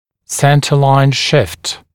[‘sentəlaɪn ʃɪft][‘сэнтэ-лайн шифт]смещение центральной линии